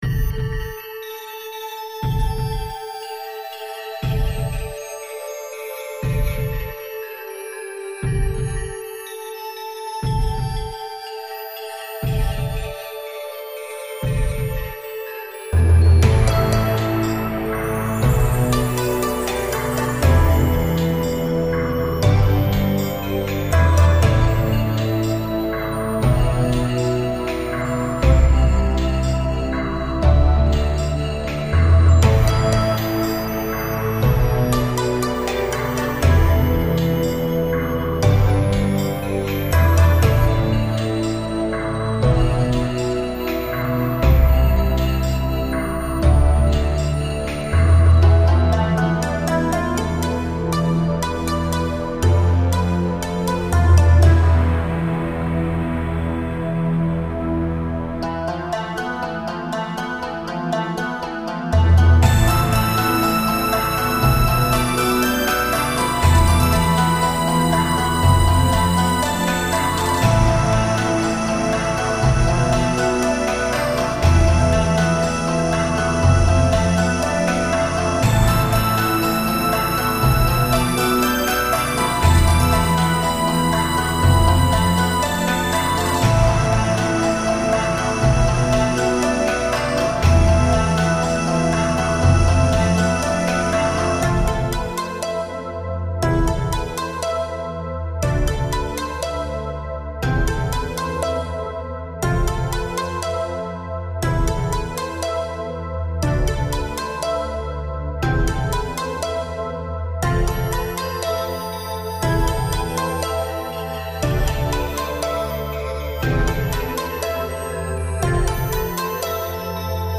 結構前に作った楽曲だけど、今きいたら結構いい感じに夜桜に合いそうですすすｗ しだれ桜の隙間から見えるおぼろ月。